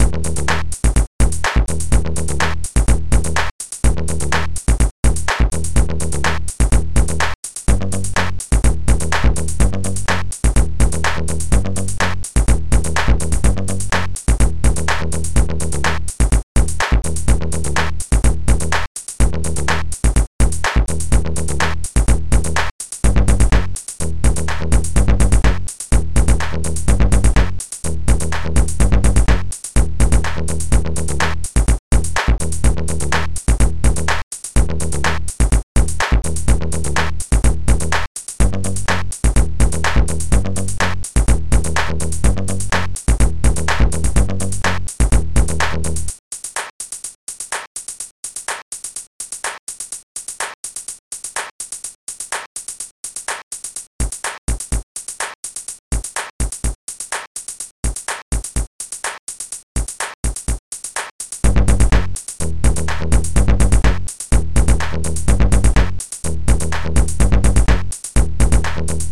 bassdrum1 popsnare1 claps1 hihat2 dxbass
Drum01.mp3